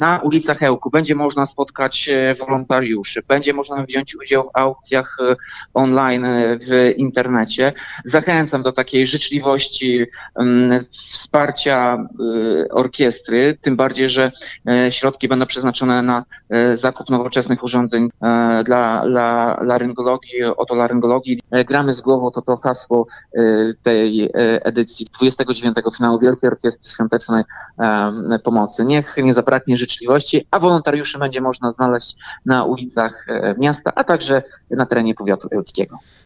– Dlatego warto uczestniczyć w atrakcjach i warto wspomagać orkiestrę – mówi prezydent Ełku Tomasz Andrukiewicz.